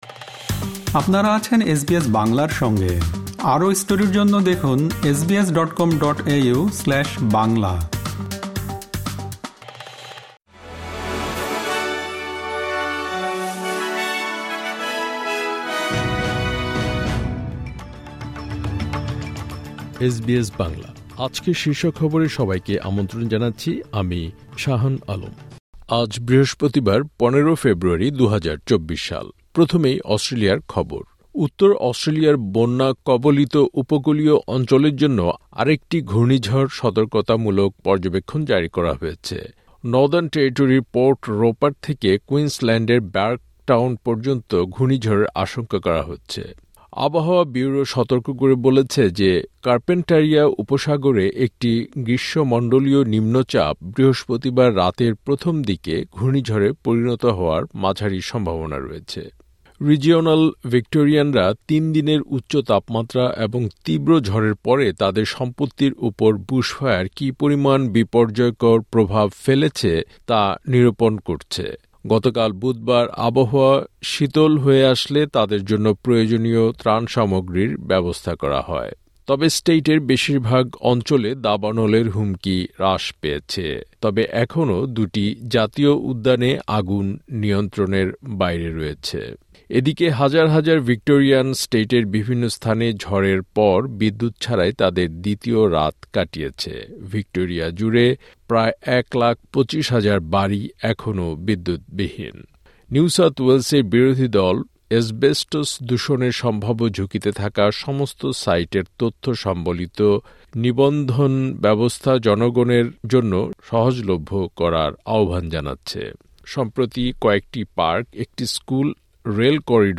এসবিএস বাংলা শীর্ষ খবর: ১৫ ফেব্রুয়ারি, ২০২৪